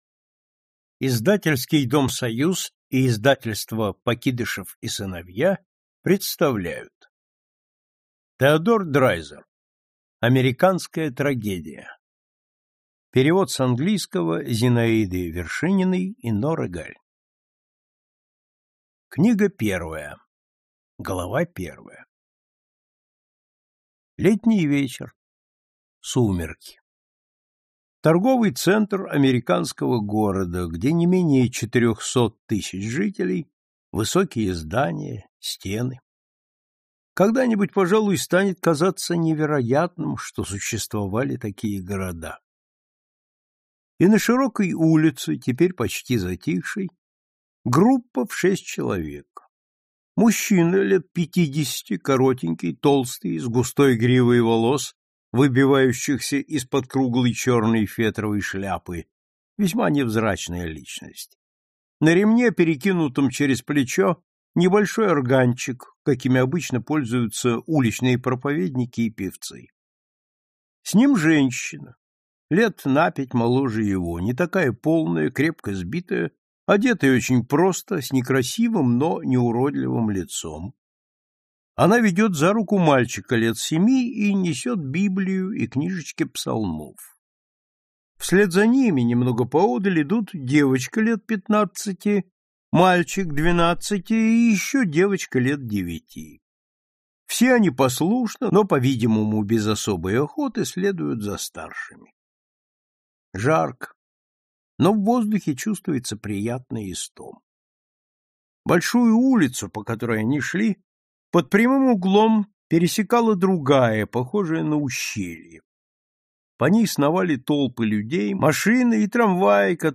Аудиокнига Американская трагедия - купить, скачать и слушать онлайн | КнигоПоиск